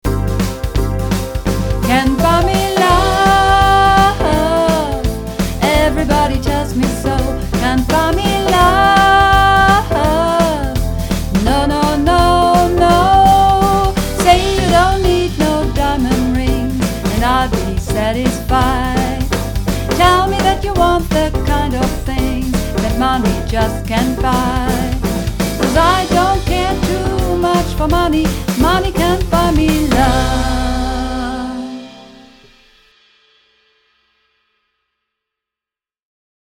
Übungsaufnahmen - Can't Buy Me Love